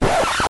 vinyl.ogg